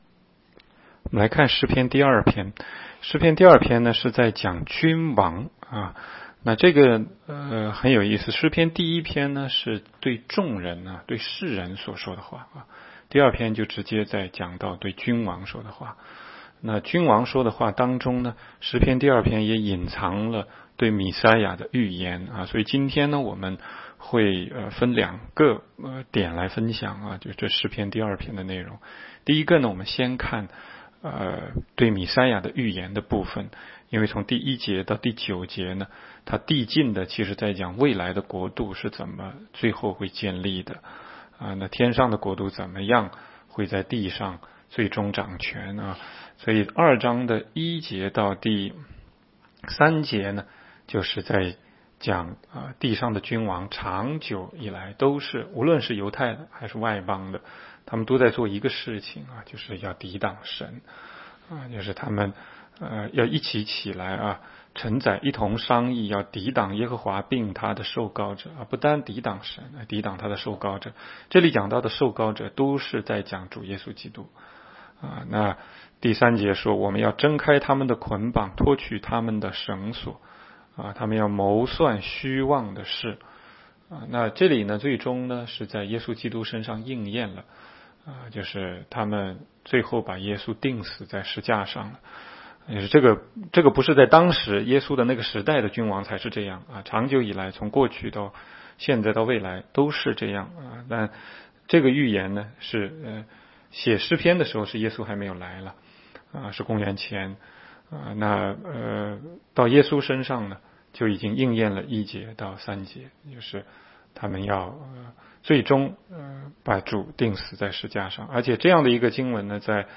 16街讲道录音 - 每日读经-《诗篇》2章